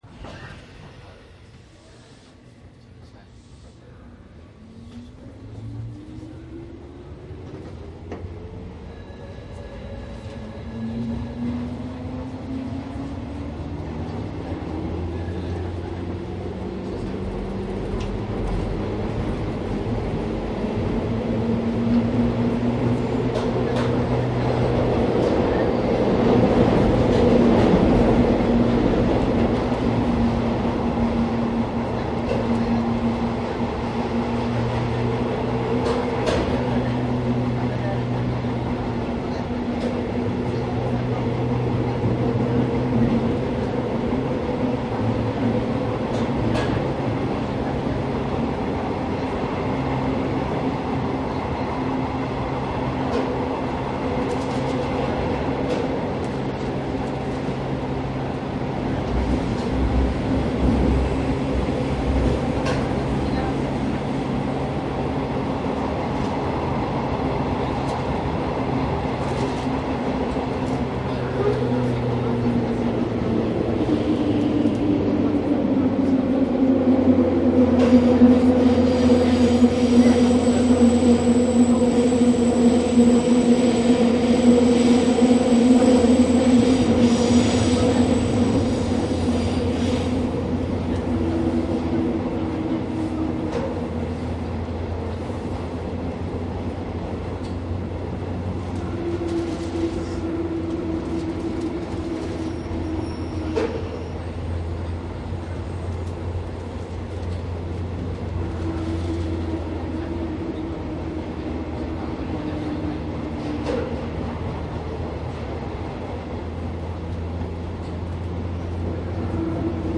営団地下鉄東西線回り JR103系 各駅停車 走行音
いずれもマイクECM959です。
実際に乗客が居る車内で録音しています。貸切ではありませんので乗客の会話が全くないわけではありません。